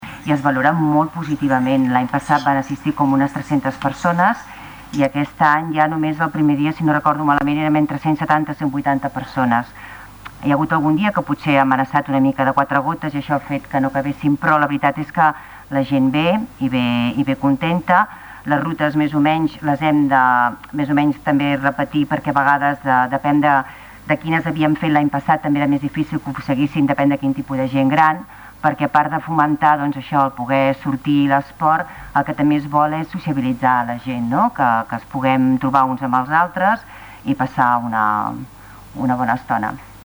La regidora de Benestar Social de l'Ajuntament de Taradell,
Lluïsa Sañé, valorava en el ple de dijous l'experiència.